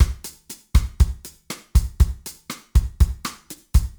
Drum Loops
Bossa 2
Straight / 120 / 2 mes
BOSSA1 - 120.mp3